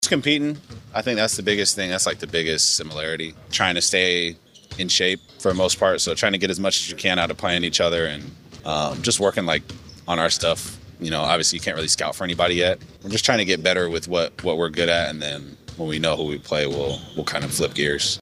Thunder guard Jalen Williams talks about OKC’s prep for the weekend.